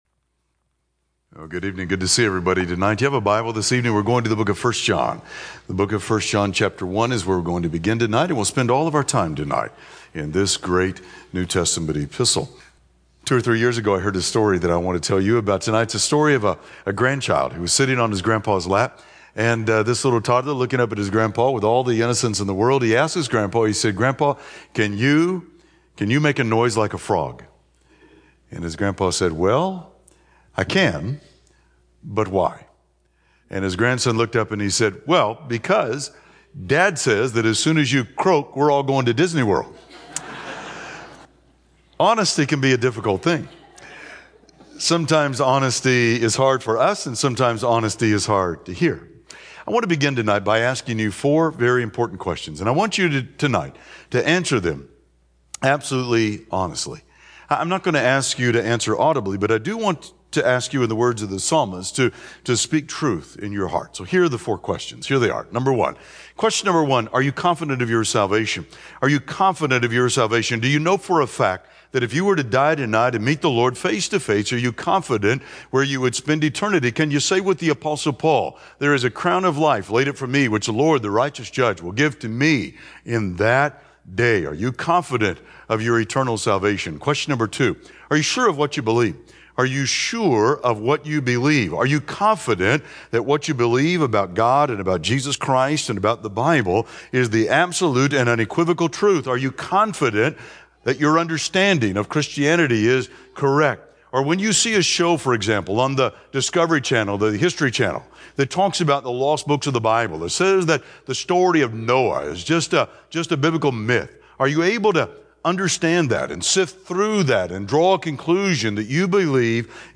Service: Wed PM Type: Sermon